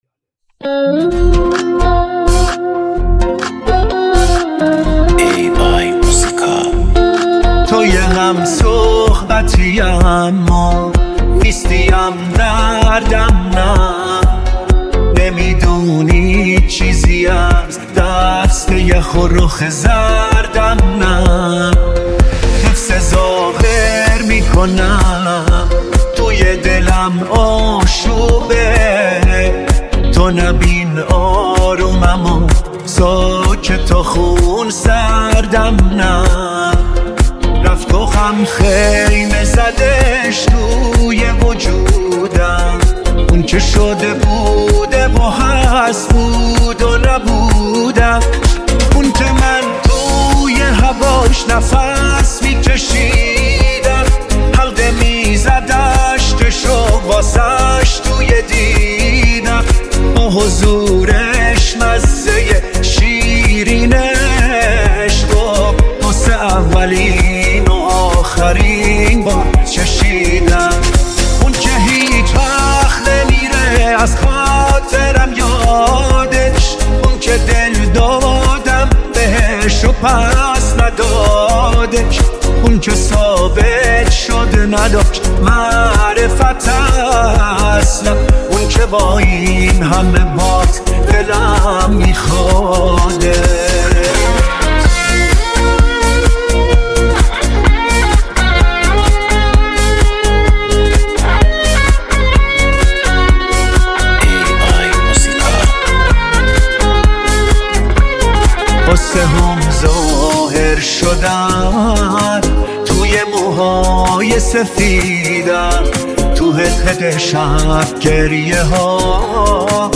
آهنگ پاپ ایرانی